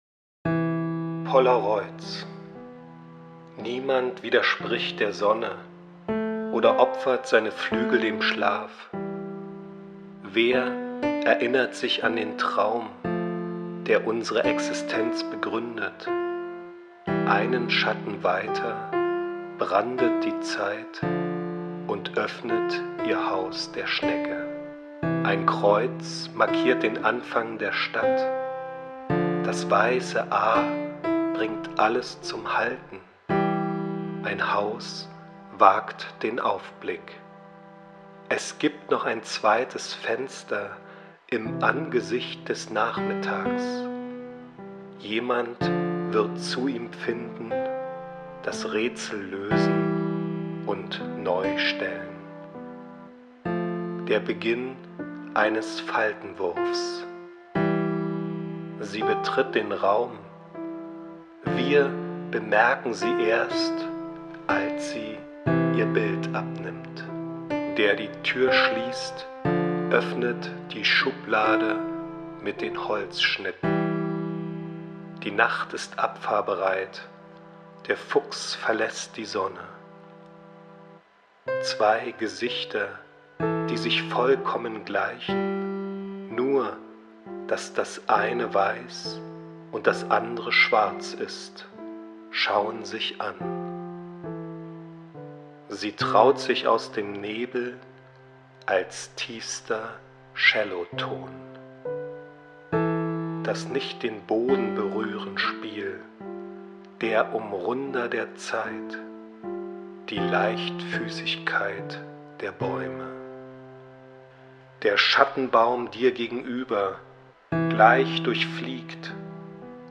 Lesung aus dem Buch